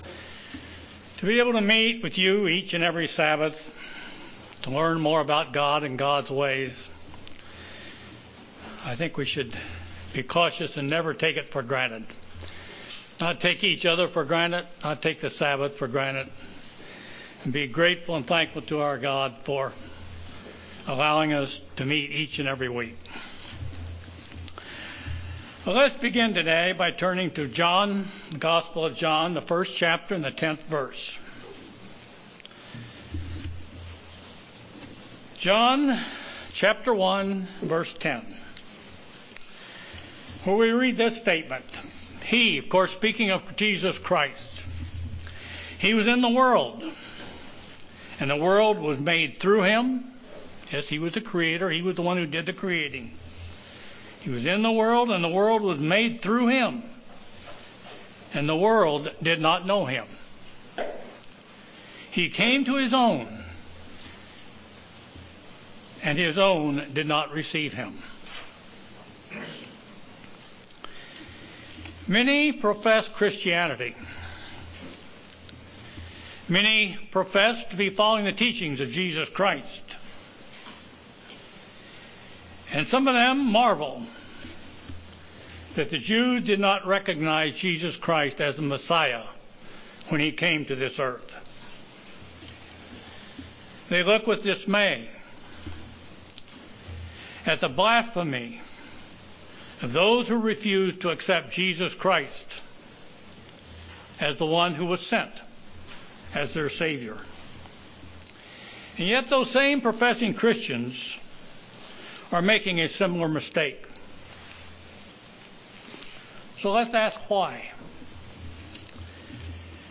Given in Kansas City, KS
UCG Sermon Studying the bible?